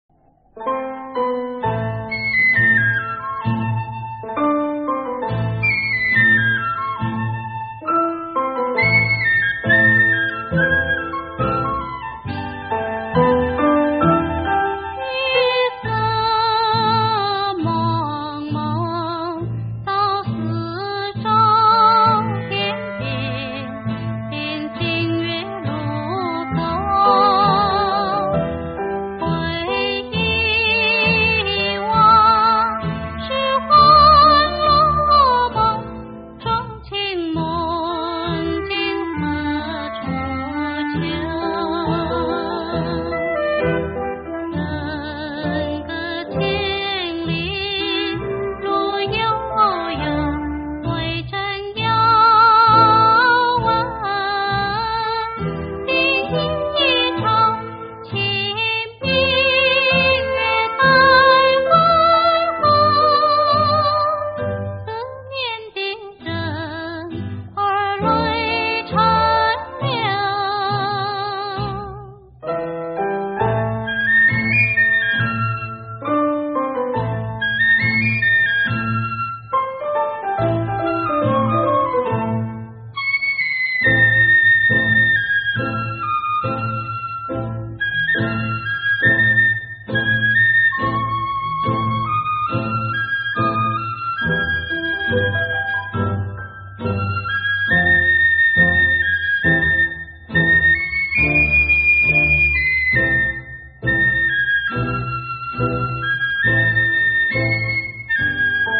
中國音樂、發燒天碟